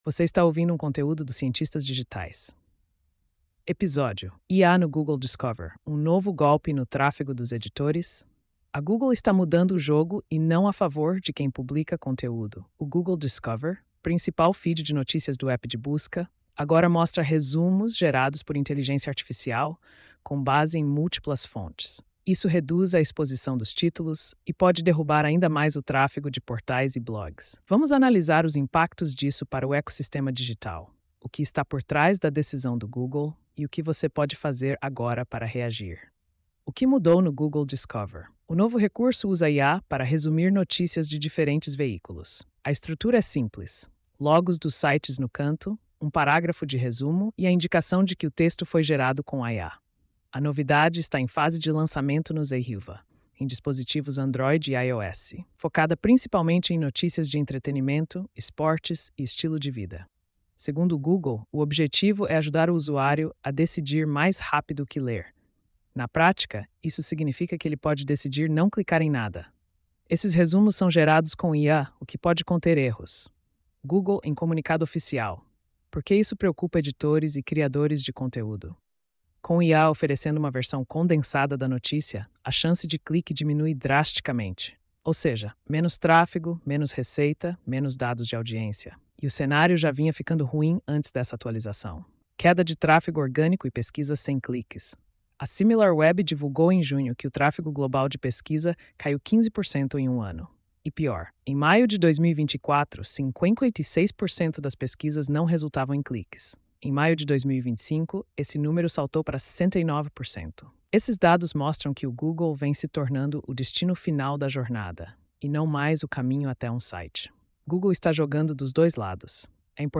post-3354-tts.mp3